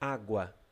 Ääntäminen
Synonyymit (runollinen) eau vague Ääntäminen France: IPA: [ɔ̃d] Haettu sana löytyi näillä lähdekielillä: ranska Käännös Ääninäyte Substantiivit 1. onda {f} 2. vaga {f} 3. água {f} PT Suku: f .